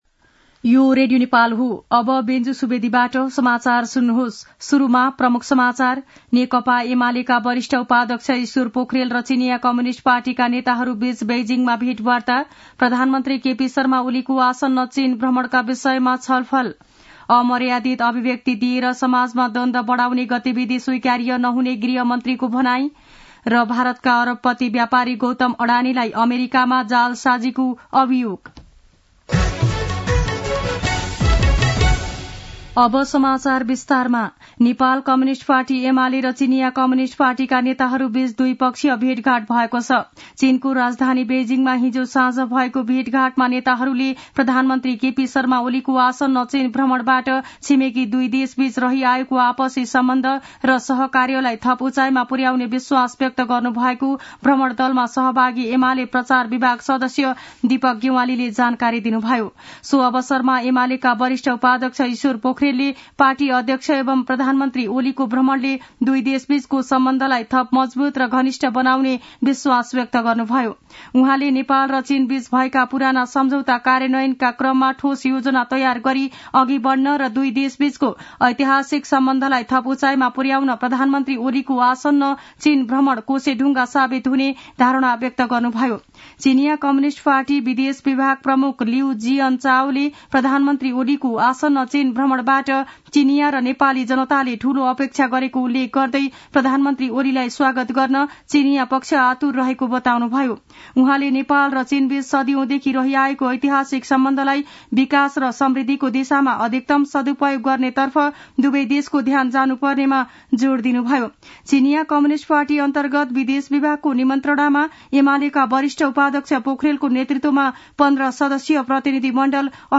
दिउँसो ३ बजेको नेपाली समाचार : ७ मंसिर , २०८१
3-pm-nepali-news-1-5.mp3